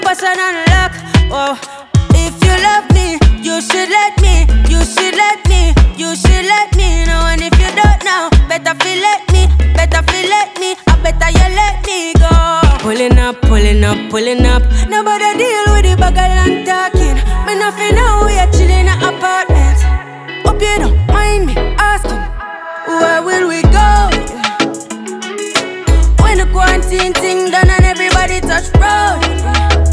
• Reggae